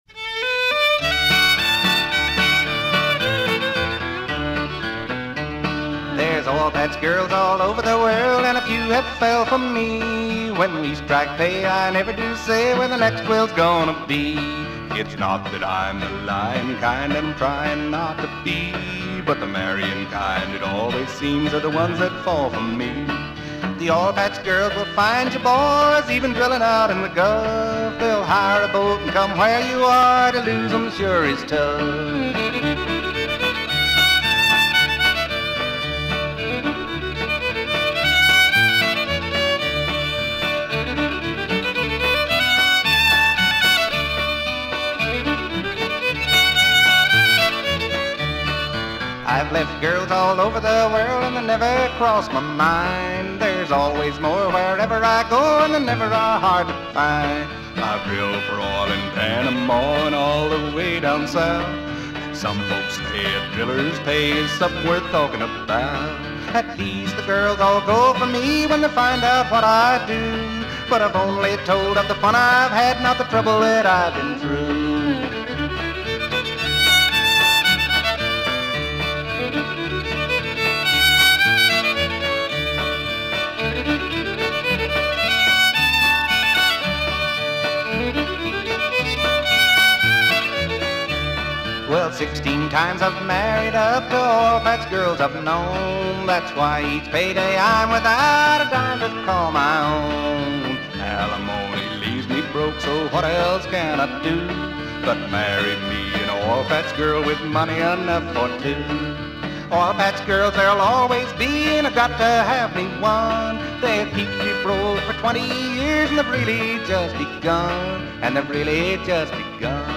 Country singer